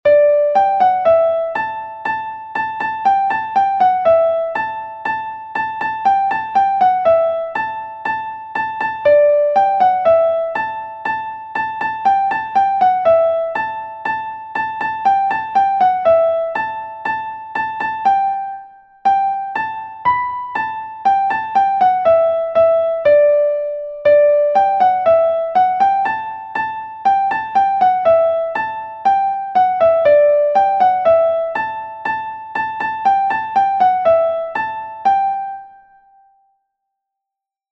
Hanter dro de Bretagne